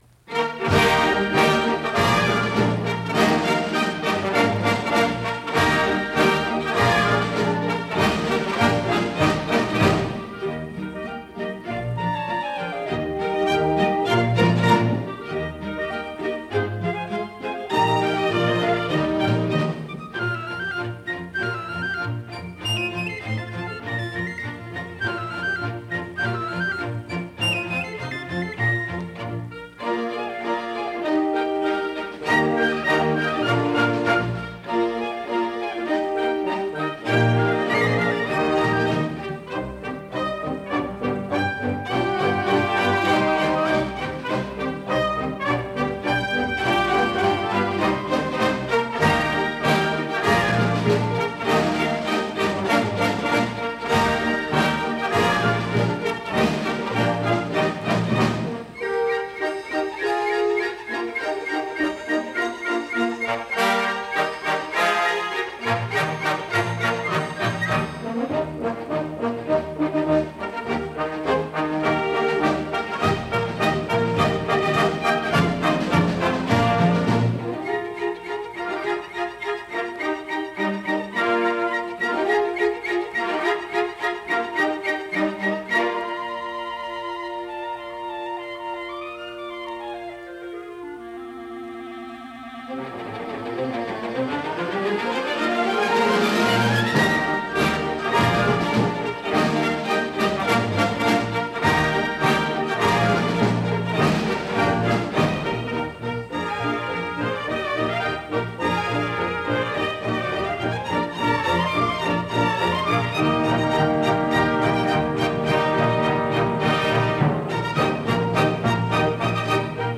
Telegraph Quick-Step – NBC Symphony
Here is a reminder – two short pieces, but very much what it was all about in the 1800’s.
Here is Meineke’s Railroad March and Deems’ Telegraph Quickstep with the NBC Studio Orchestra (identified as The NBC Symphony, but I doubt it) for the radio series Music Of The New World, broadcast on February 4, 1943.